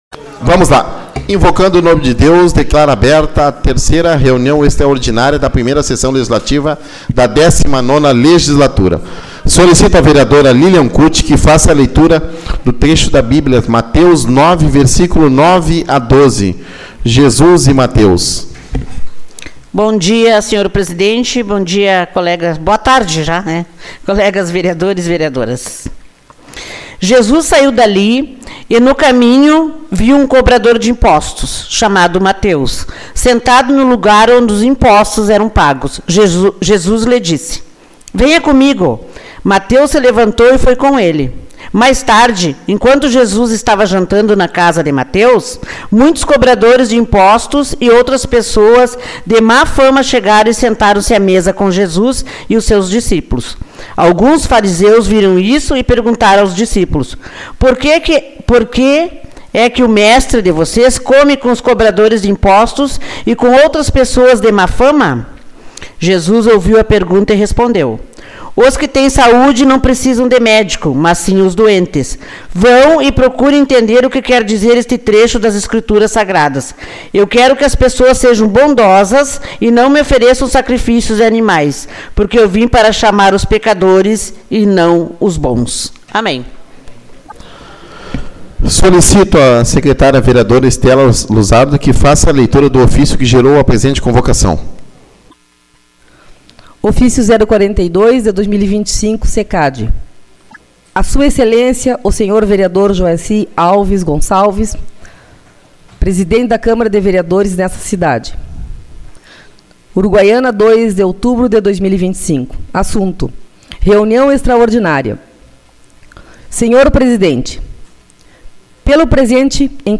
03/10 - Reunião Extraordinária